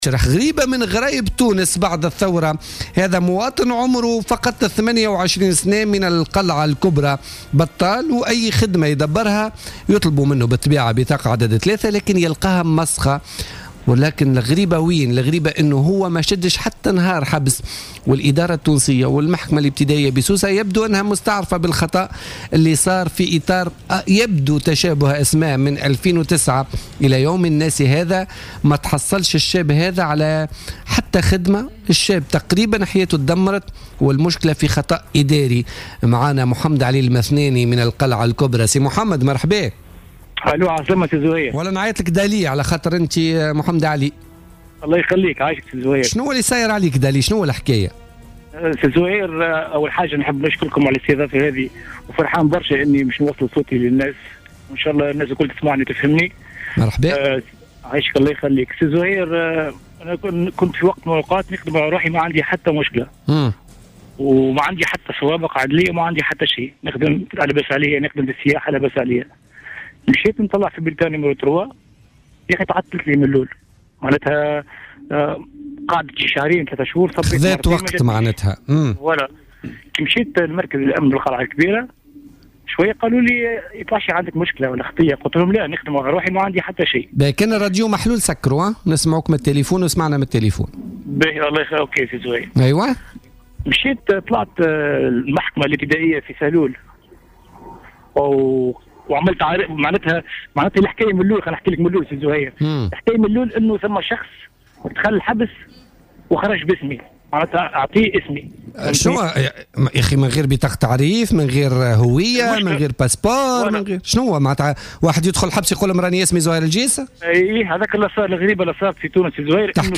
وقال لـ"الجوهرة أف أم" في اتصال هاتفي ببرنامج "بوليتيكا"، إنه لدى محاولاته لاستخراج بطاقة عدد3 اكتشف ان له سوابق عدلية "وهمية" وتفطن لاحقا أنه تم استخدام هويته من أحد أقربائه وهو ملاحق قضائيا واستغل هويته زورا.